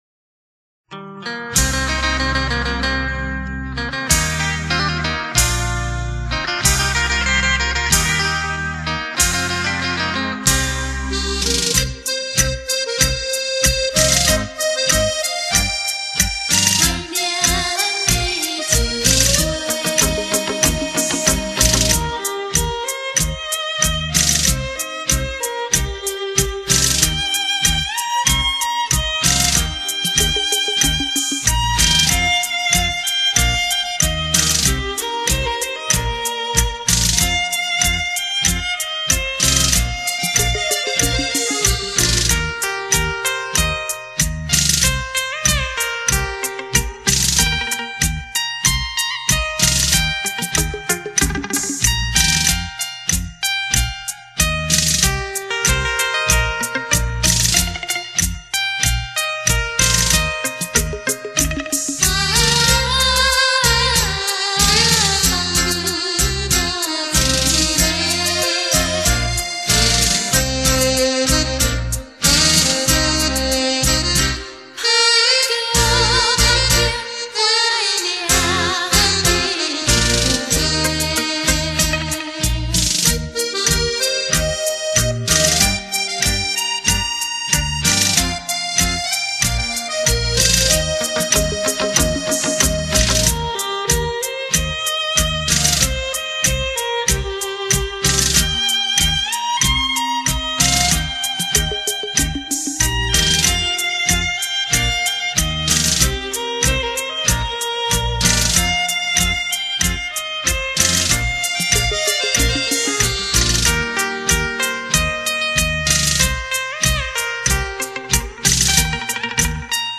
与前3辑不同的是，第④⑤辑的伴奏全部采用了双电子琴并
入了女和声，很值得一听！